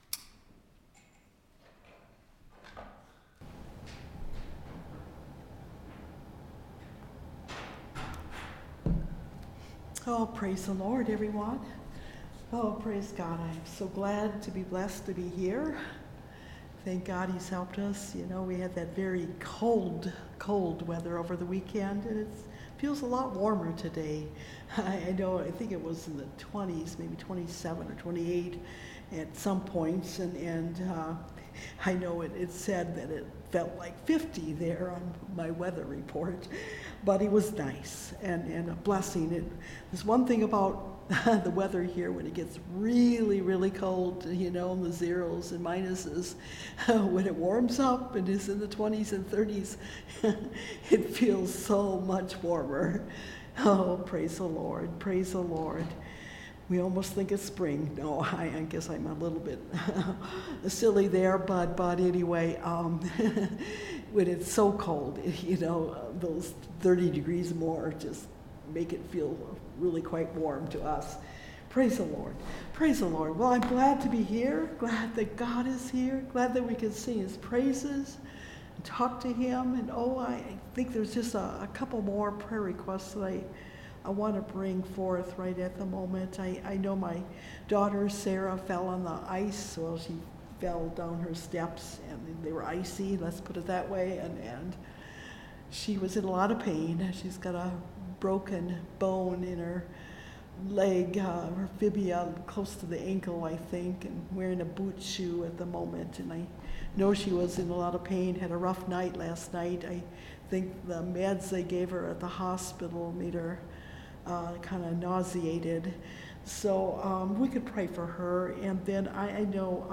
Service Type: Wednesday Night Bible Study